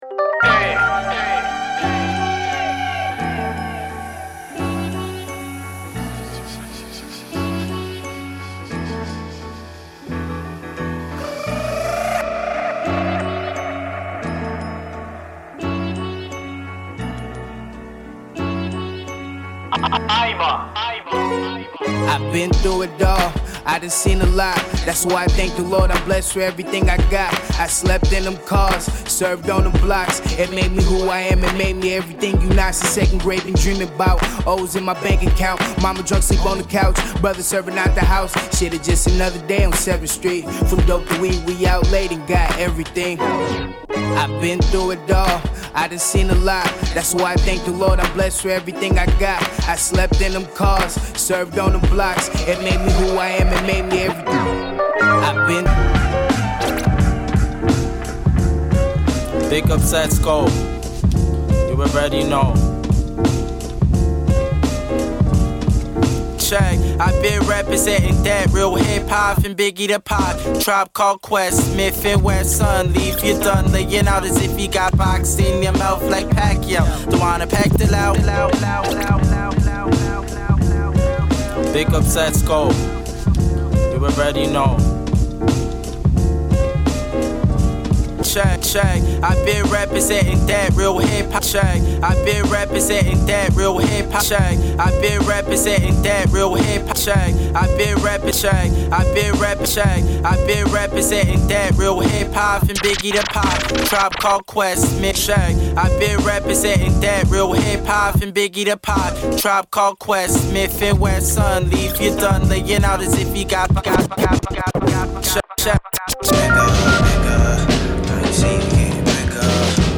後半は重厚な中にも耳に馴染むメロディアスな楽曲が続き
＊試聴はダイジェストです。
Mix CD
Underground Hip Hop その他のおすすめレコード